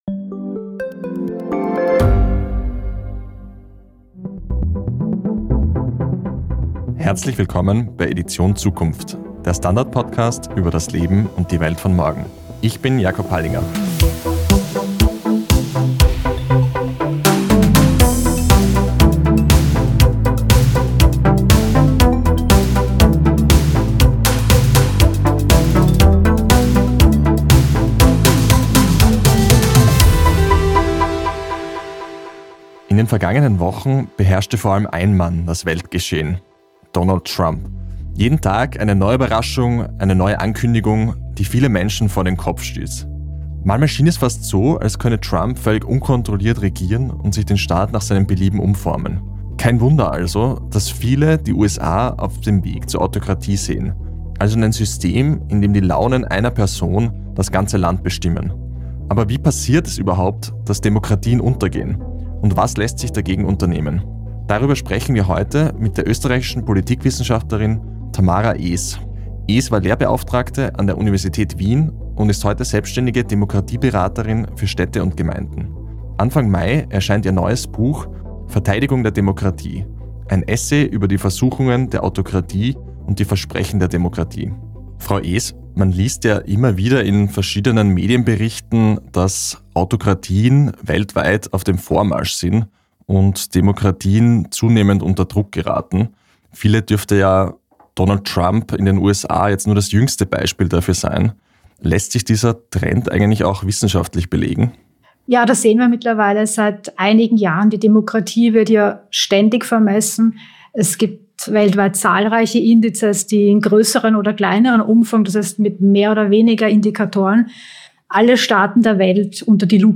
Die Redaktion spricht mit Experten über Entwicklungen, die unseren Alltag verändern - von künstlicher Intelligenz und Robotern bis hin zu Migration und Klimawandel.